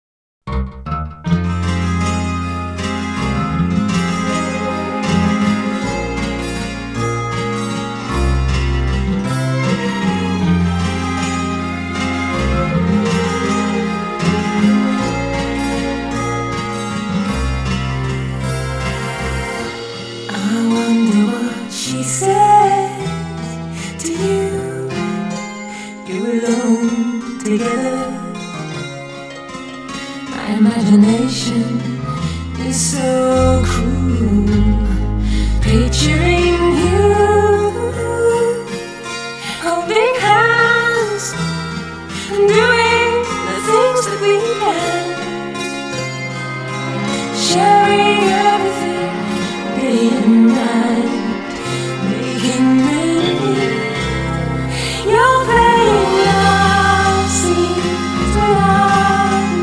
And this minute in the processed version.